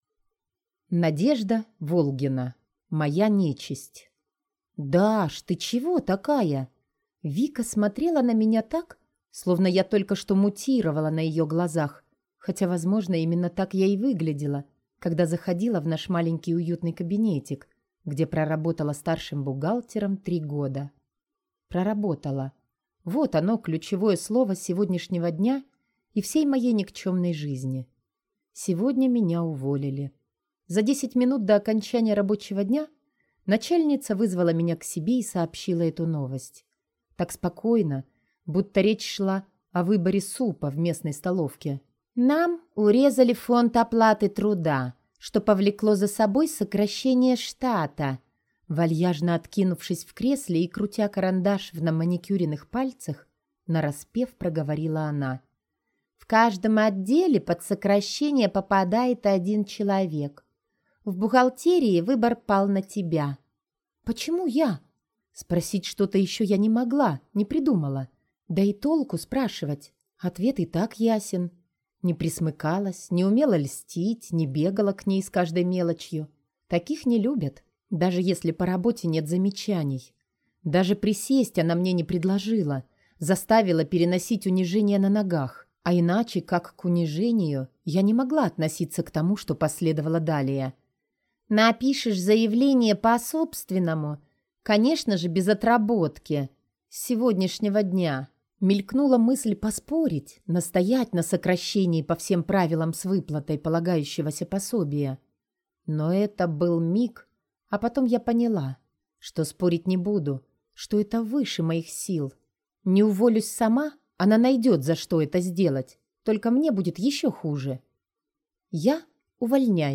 Аудиокнига Моя нечисть | Библиотека аудиокниг
Прослушать и бесплатно скачать фрагмент аудиокниги